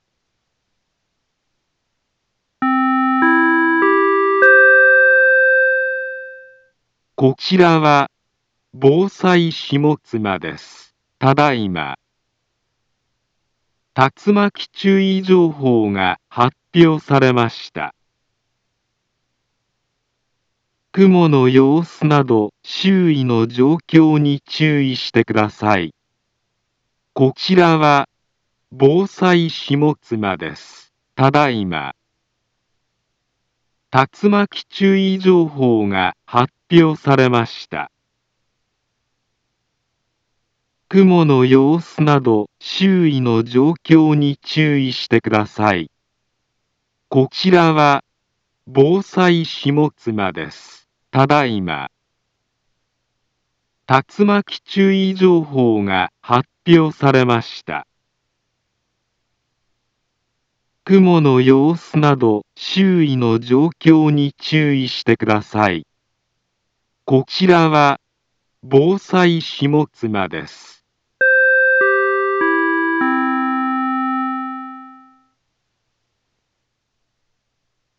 Back Home Ｊアラート情報 音声放送 再生 災害情報 カテゴリ：J-ALERT 登録日時：2023-07-10 21:25:09 インフォメーション：茨城県南部は、竜巻などの激しい突風が発生しやすい気象状況になっています。